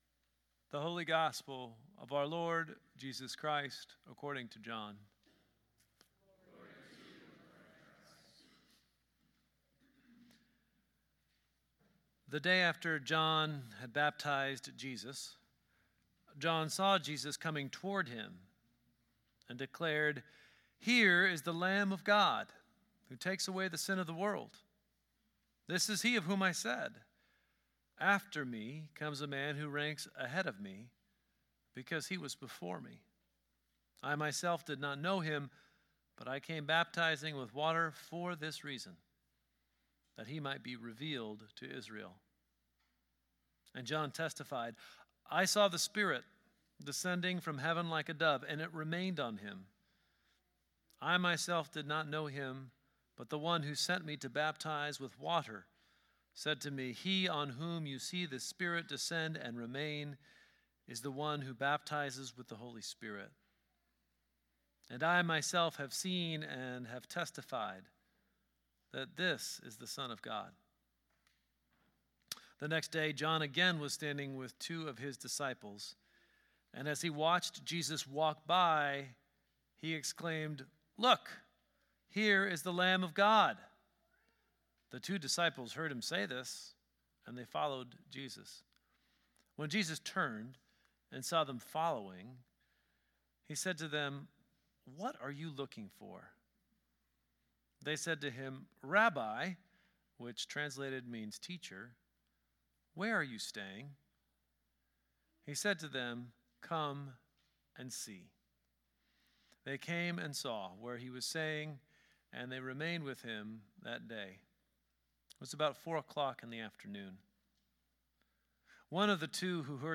Gospel Reading: John 1:29-42